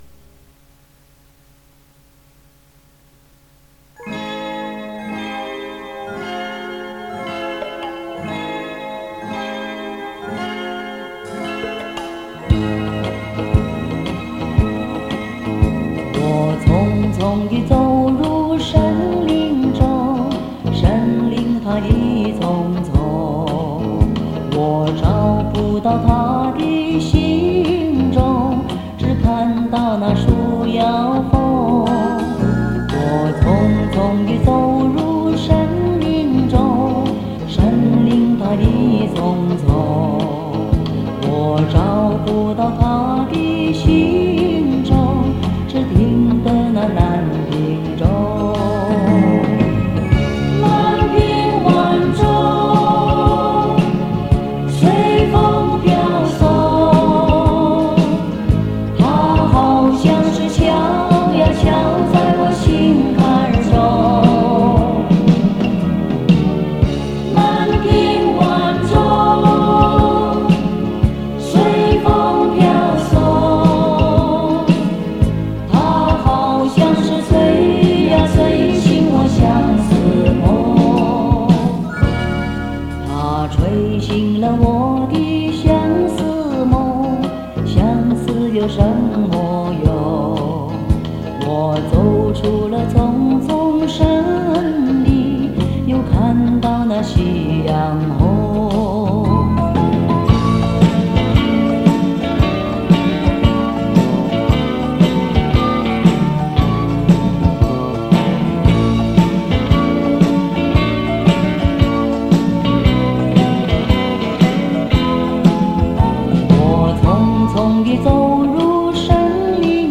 磁带数字化：2023-02-25